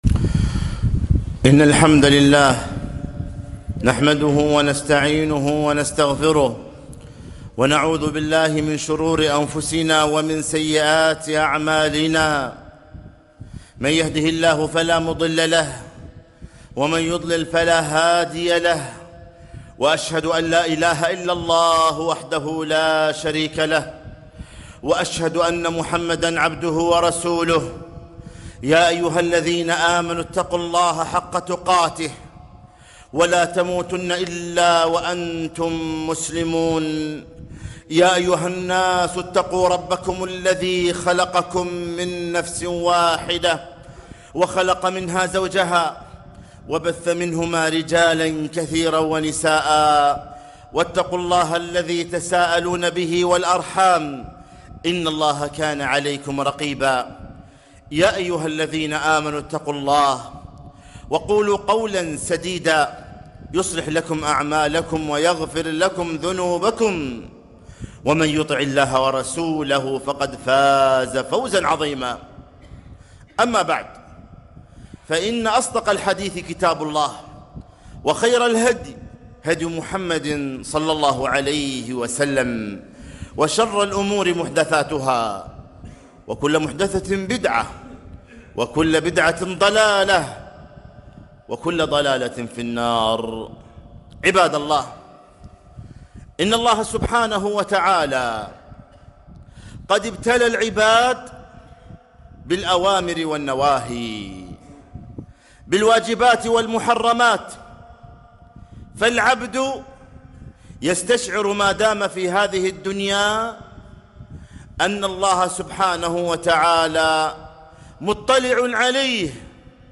خطبة - من ترك شيئاً لله عوضه الله خيراً منه